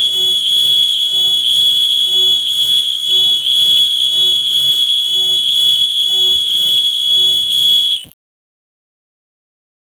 Harsh alarm beep with distortion, abrupt stop at the end
harsh-alarm-beep-with-dis-njyrrypy.wav